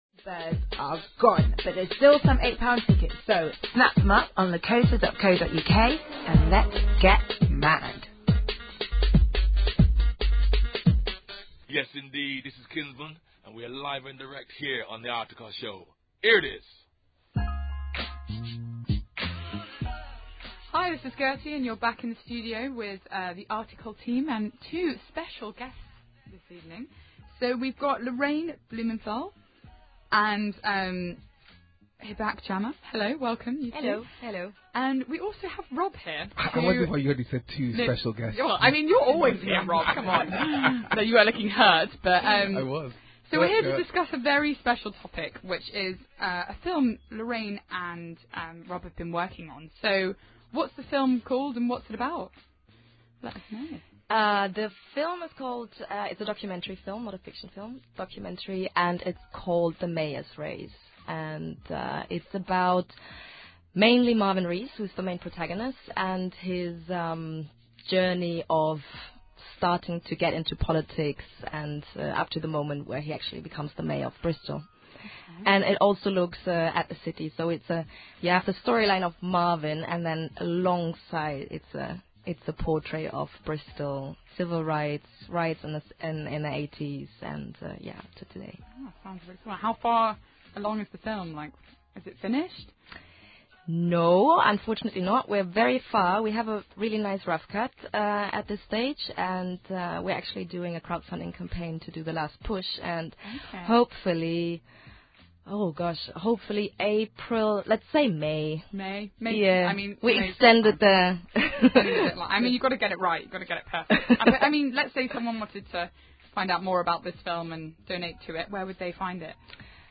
Interview on Ujima Radio – May 2019